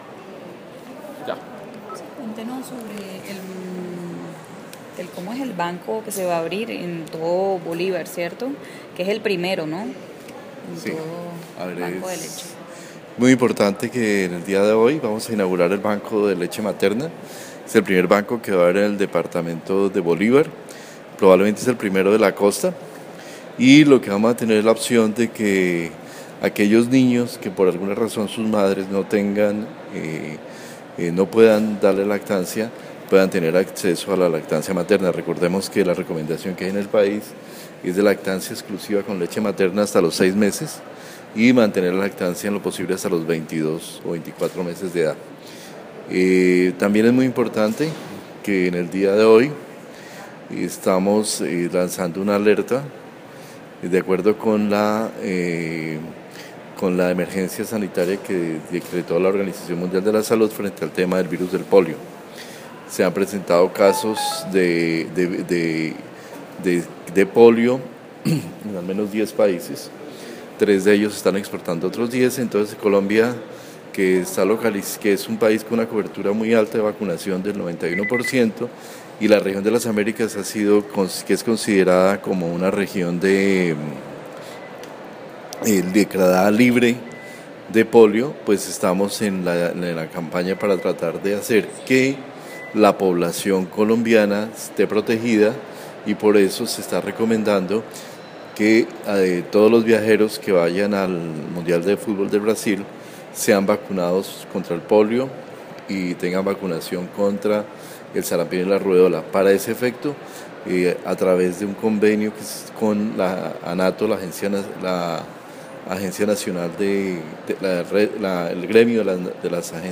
ViceSalud, Fernando Ruiz Gómez habla sobre las recomendaciones a viajeros que van a Brasil